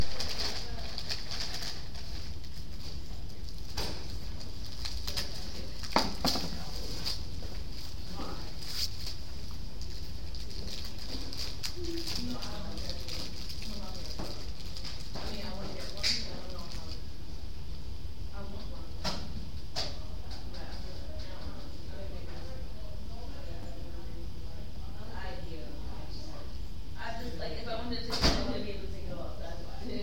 Field Recording #9
SOUND CLIP: Breslin Hall 111 LOCATION: Breslin Hall 111 SOUNDS HEARD: food wrapper crinkling, footsteps, people talking, door slamming